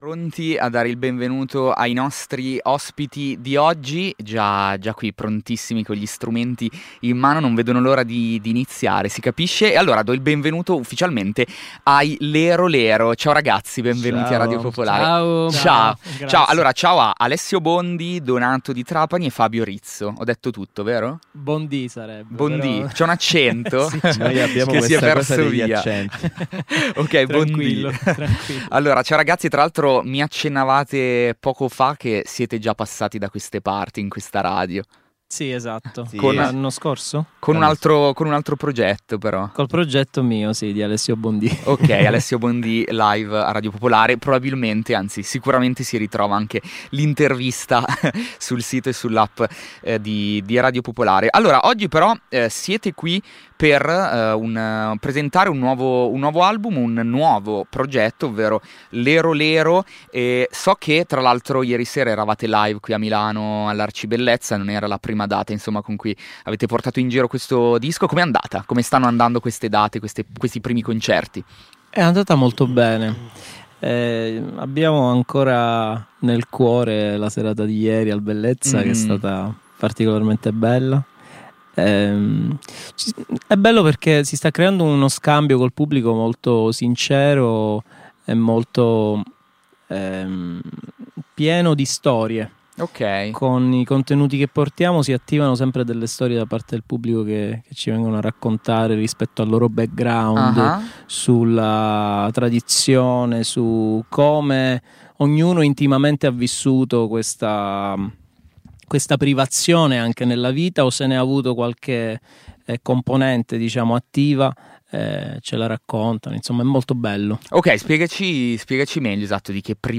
collettivo palermitano
l’intervista e MiniLive